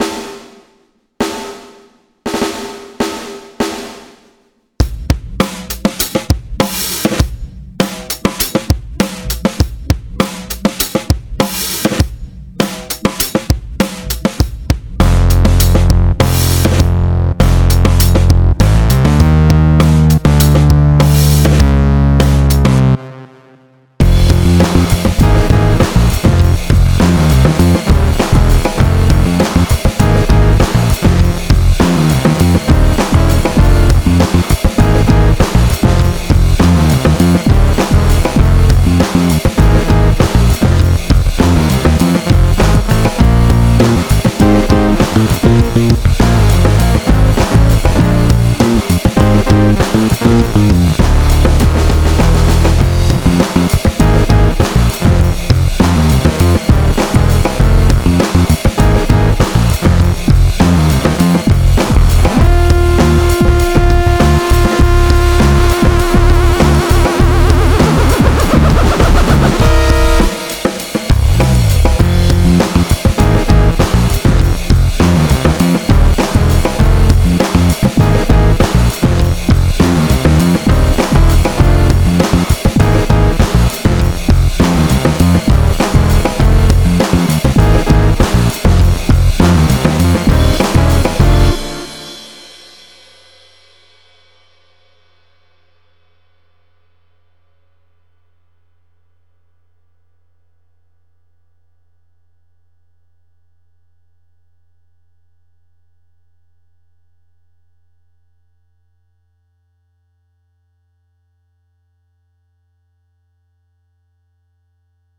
Backing 100 bpm